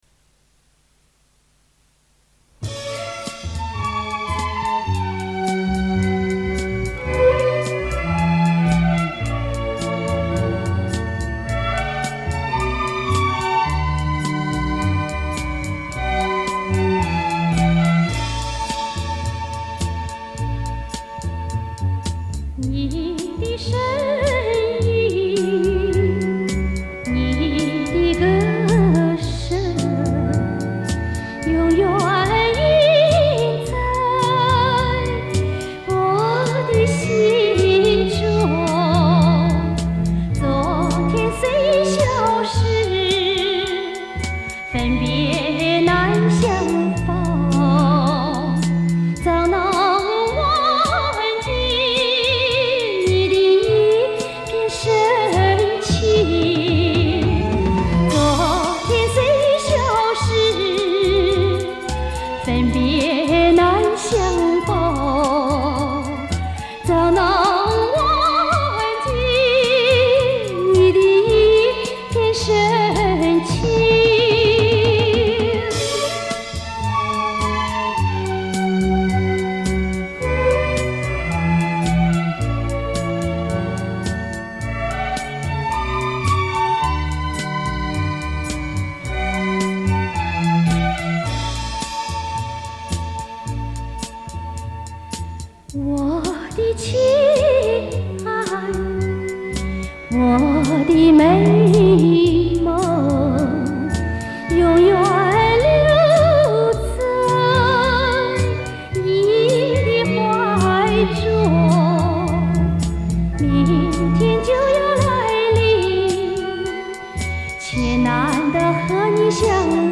磁带数字化